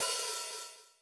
RX5 HI-HATOP.wav